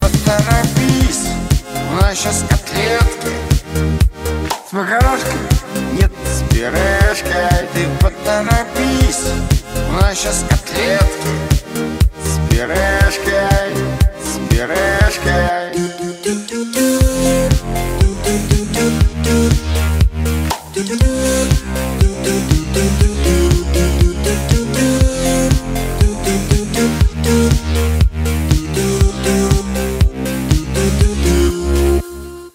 позитивные
добрые